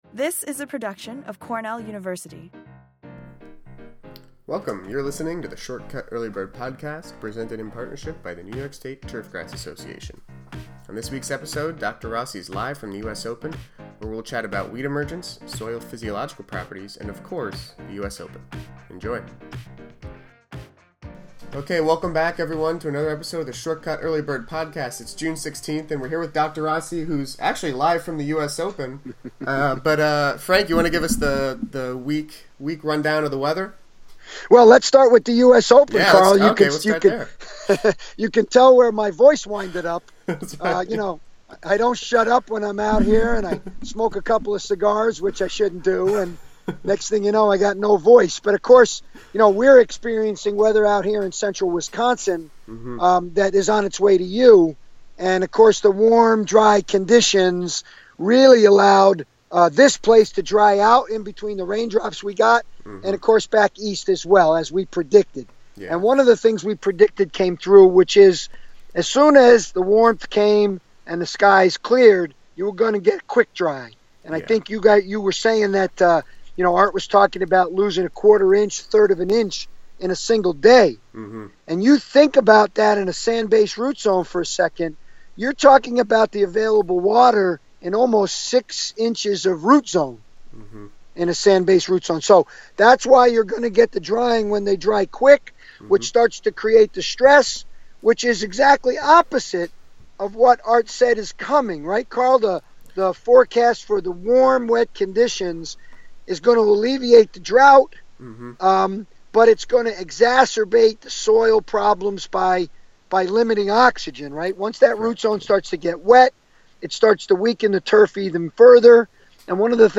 Live from the US Open! Soil matters and more. Week 12 ‘ShortCUTT Early Bird’ podcast available now